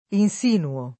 vai all'elenco alfabetico delle voci ingrandisci il carattere 100% rimpicciolisci il carattere stampa invia tramite posta elettronica codividi su Facebook insinuare [ in S inu- # re ] v.; insinuo [ in S& nuo ] — pres. 1a pl. insinuiamo [ in S inu L# mo ]